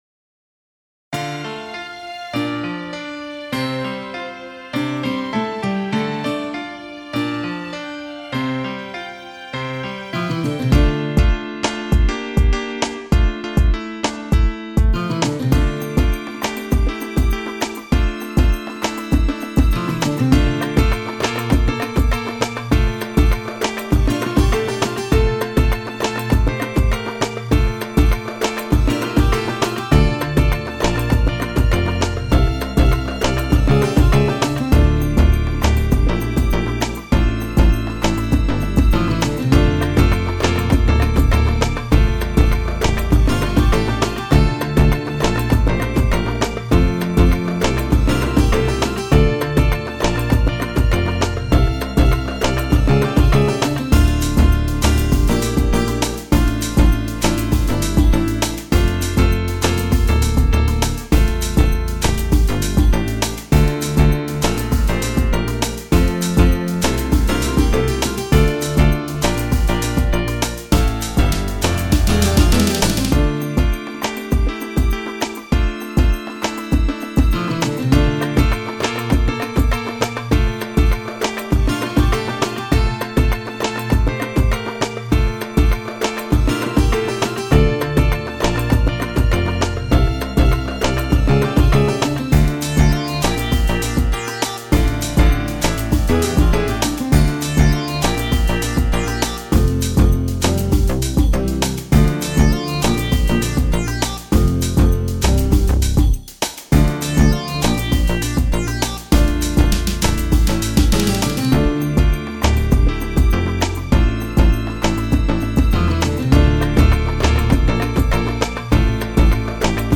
El Himno
HIMNO TRICENTENARIO TECNO...wma